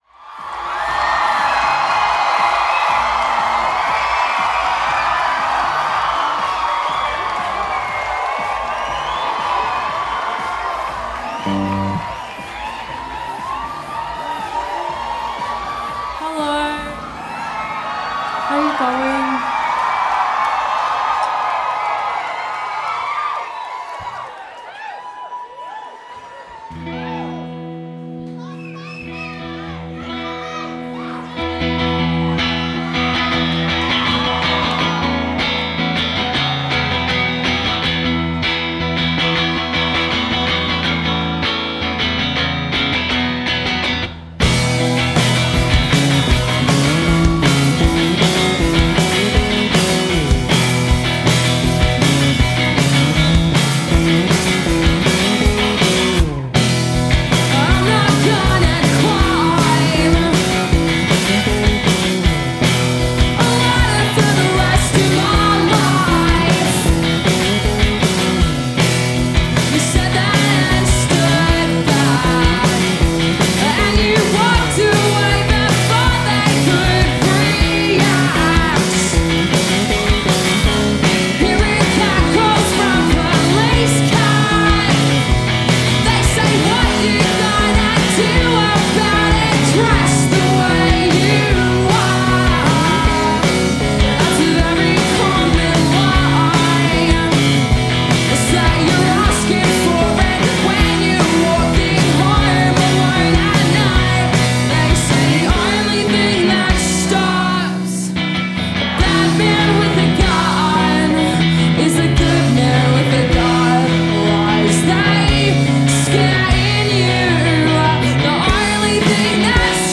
Live at Metro Theatre, Sydney Australia
recorded at Metro Theatre in Sydney
guitarist
lead bassist
drummer
Power Emo.